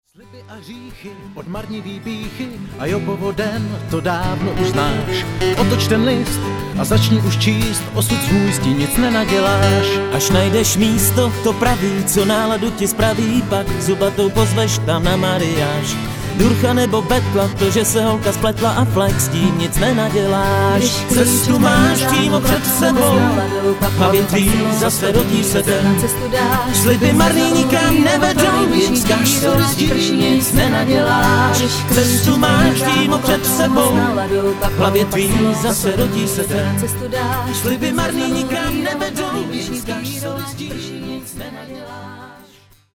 česká folková kapela z Pelhřimova.